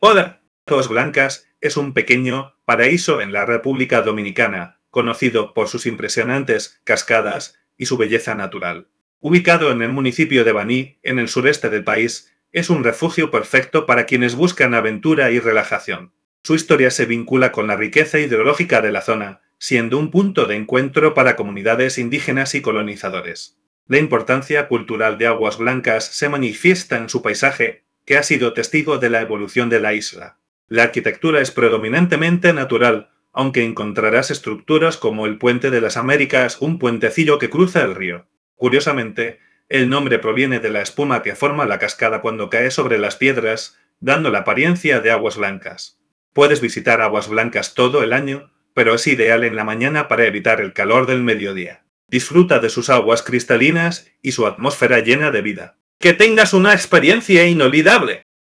karibeo_api / tts / cache / 68d603183d4368dacbfb527293a5de36.wav